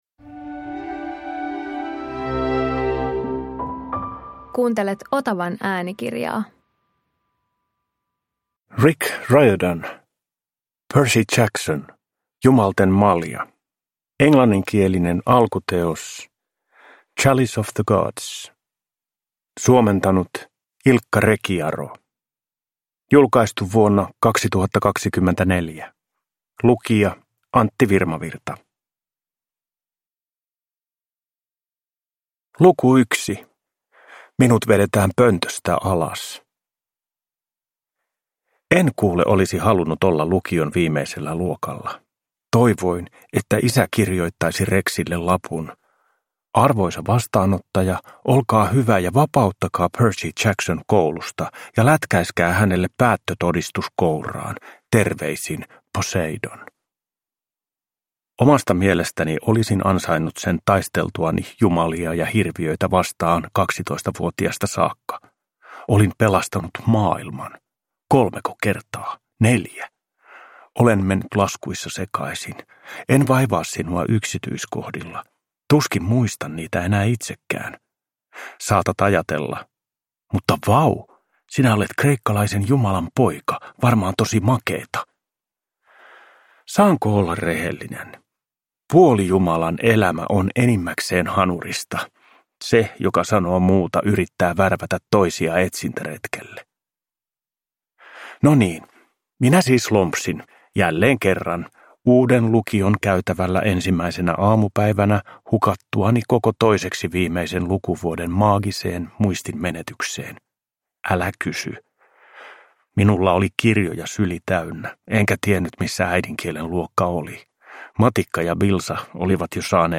Percy Jackson - Jumalten malja – Ljudbok